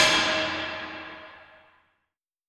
FX Percs.wav